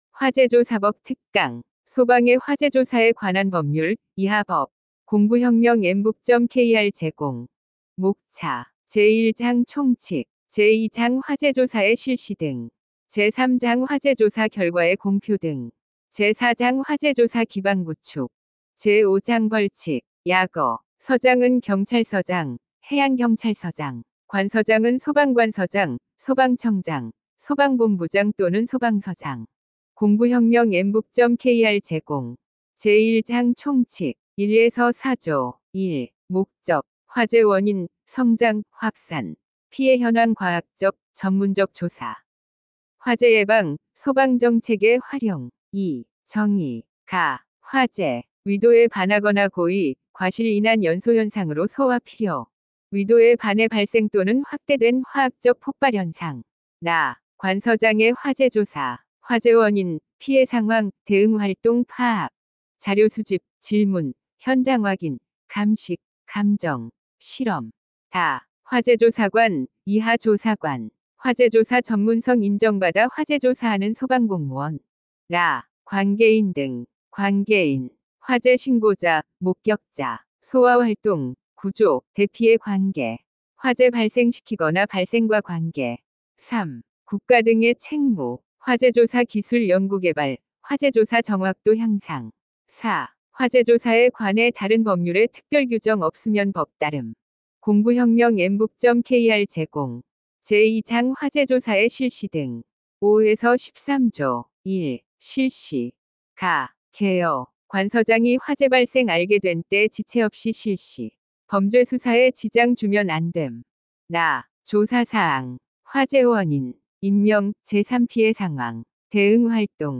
강사 : 인공지능(AI)
– 인공지능 성우 이용 오디오 강의
▶ 강의 샘플(3분)
정리한 내용을 TTS(Text to Speech) 기술을 이용해 인공지능 성우가 또렷한 음성으로 낭독해 귀로 들어도 98~99% 이해가 가능합니다.